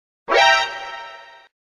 جلوه های صوتی
دانلود صدای علامت تعجب از ساعد نیوز با لینک مستقیم و کیفیت بالا